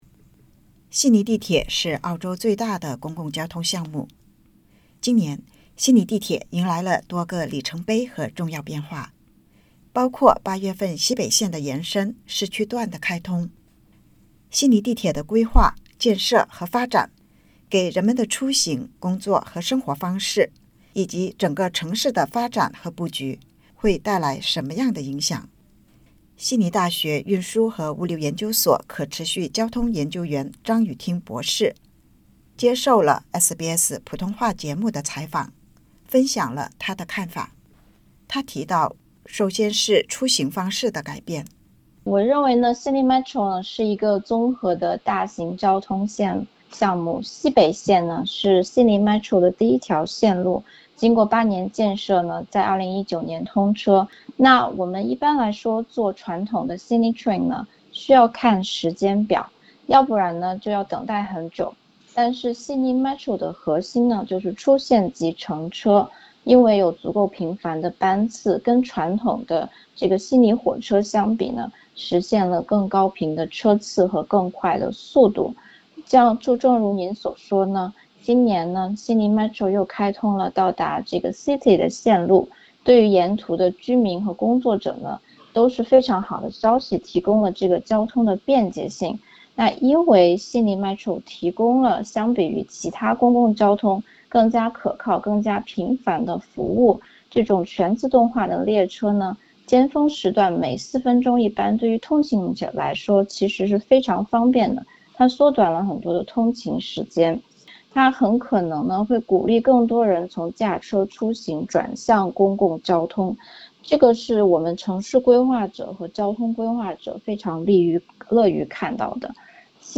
悉尼地铁是澳洲最大的公共交通项目，五年间，两大地铁路线开通，给许多人的出行、工作以及生活带来变化，悉尼地铁标识“M”正在成为许多人日常生活中的一部分。（点击播放键收听完整采访）